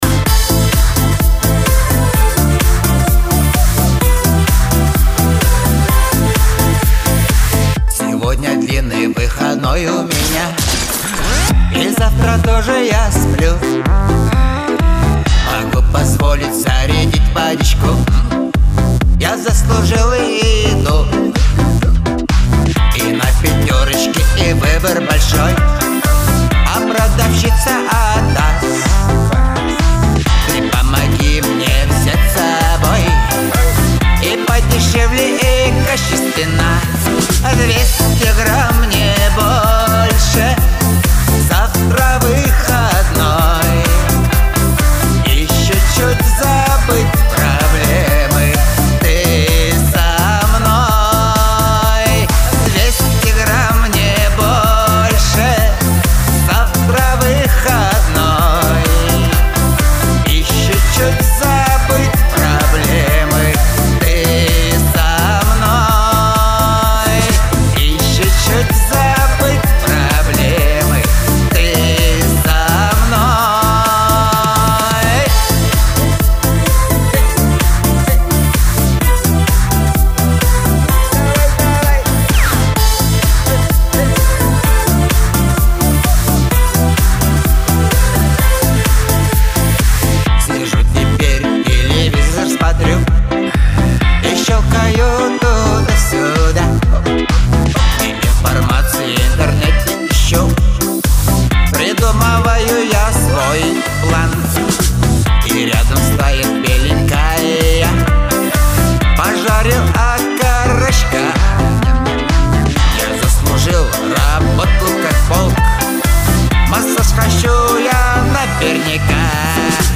Лирика
Веселая музыка